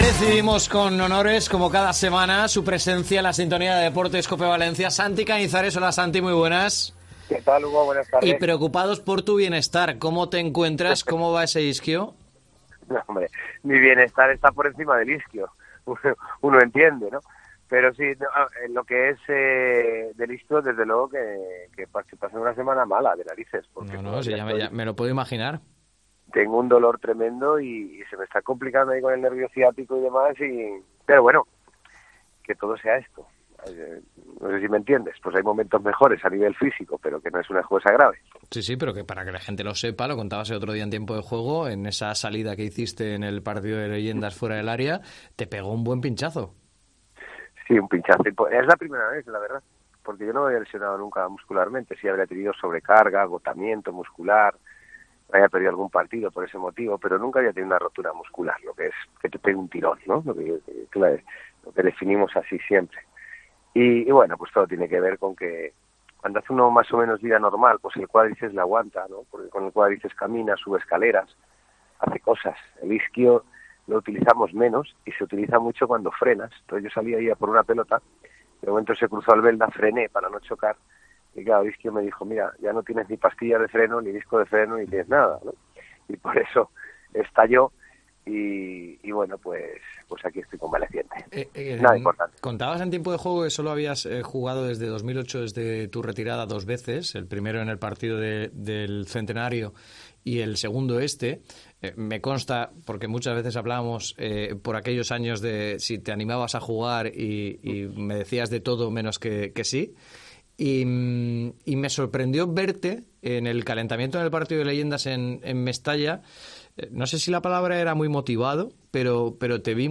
Santi Cañizares se ha pasado por los micrófonos de Deportes COPE Valencia para repasar toda la actualidad del Valencia CF y transmitir la emoción con la que vivió el Partido del Triplete en Mestalla el pasado viernes.